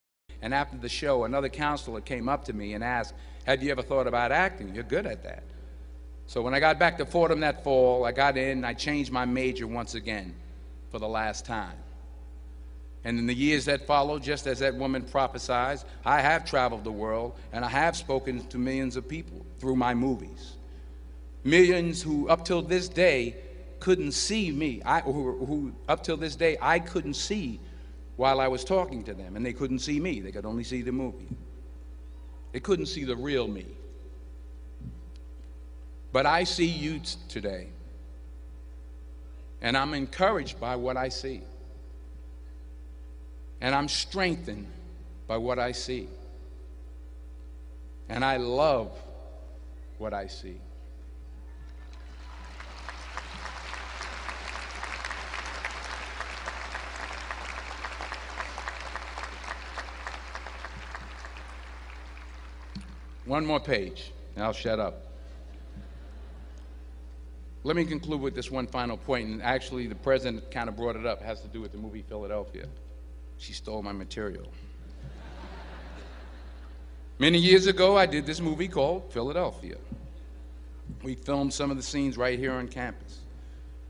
公众人物毕业演讲第430期:丹泽尔2011宾夕法尼亚大学(14) 听力文件下载—在线英语听力室